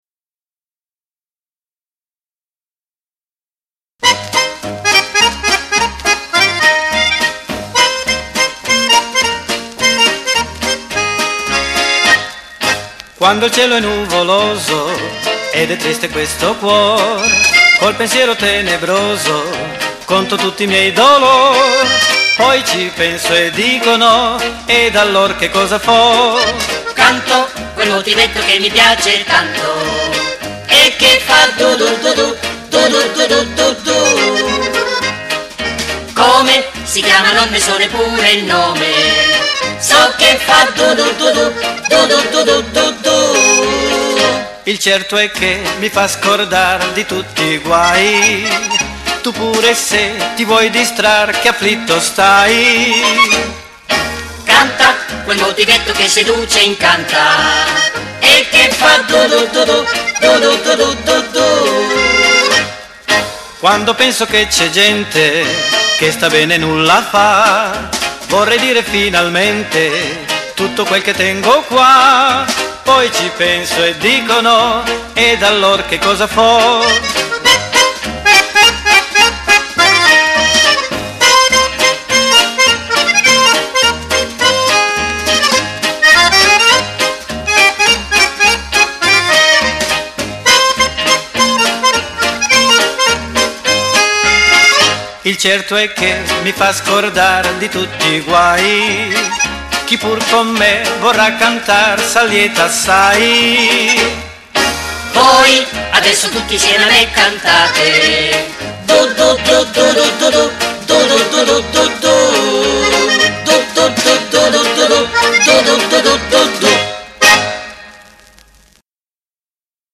FOX MODERATO